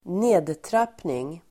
Ladda ner uttalet
Uttal: [²n'e:dtrap:ning]